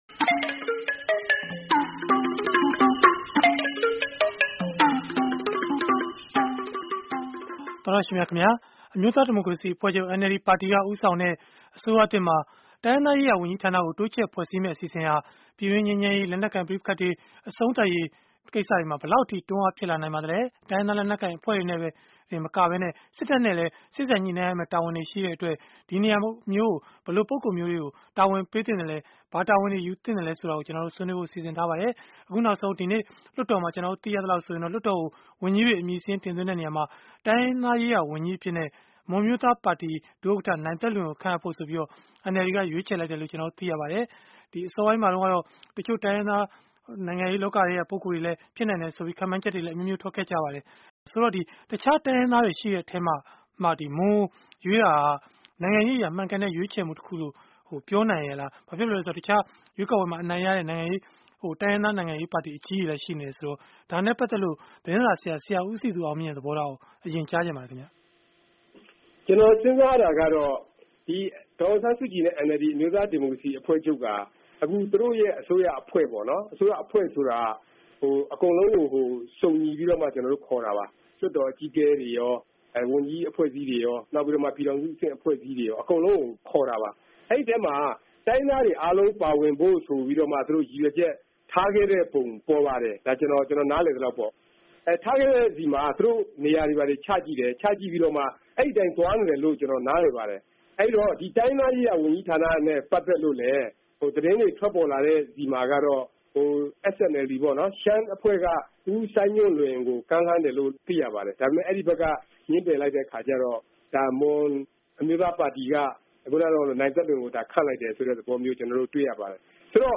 NLD ဦးဆောင်တဲ့ အစိုးရသစ်မှာ တိုးချဲ့ဖွဲ့စည်းလိုက်တဲ့ တိုင်းရင်းသားရေးရာ ဝန်ကြီးဌာနဟာ တိုင်းရင်းသားတွေအရေး၊ ပြည်တွင်းငြိမ်းချမ်းရေး၊ လက်နက်ကိုင် ပဋိပက္ခတွေ အဆုံးသတ်ရေး ဘယ်လောက်အထိ အထောက်အကူ ဖြစ်လာနိုင်ပါသလဲ? ဆိုတာကို အင်္ဂါနေ့ည တိုက်ရိုက်လေလှိုင်း အစီအစဉ်မှာ ဆွေးနွေးထားပါတယ်။